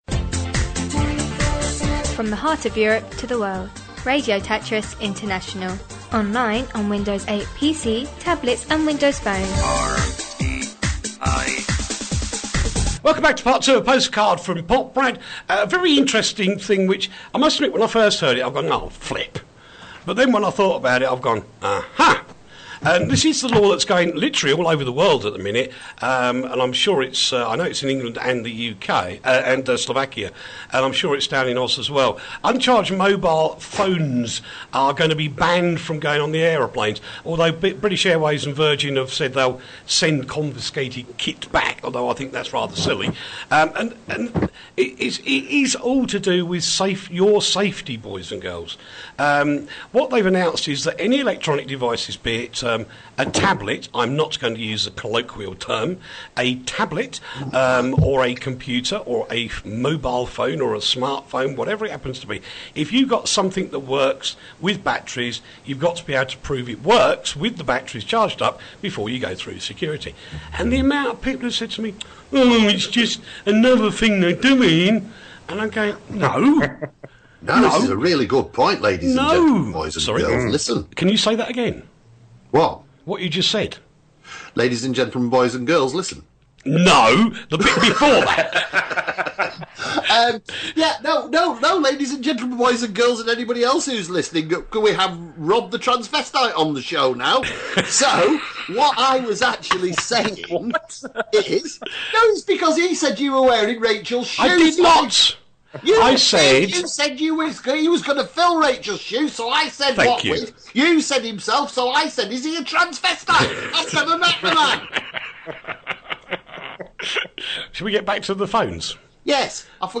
Everything from Tech to Tabloid News.